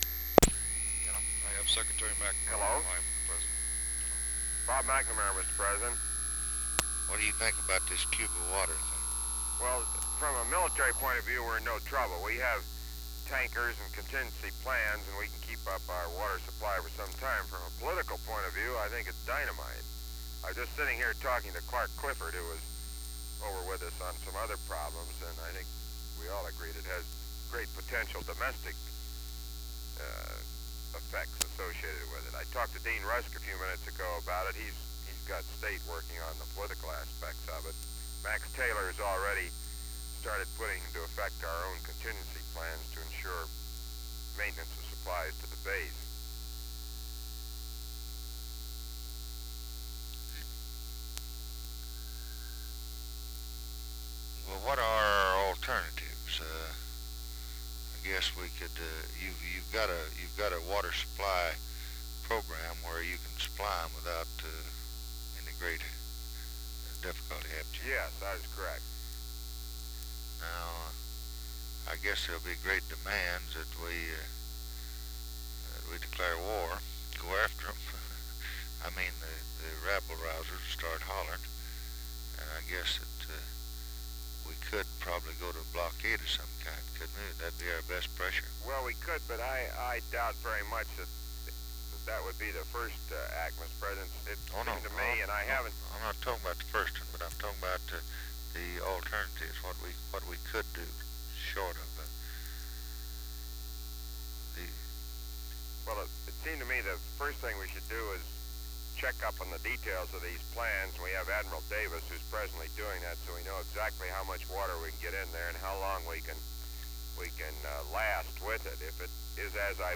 Conversation with ROBERT MCNAMARA, February 6, 1964
Secret White House Tapes